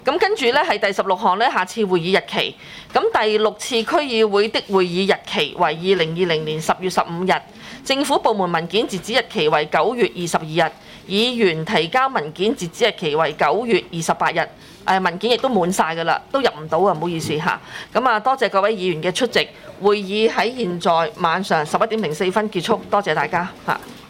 区议会大会的录音记录
中西区区议会会议室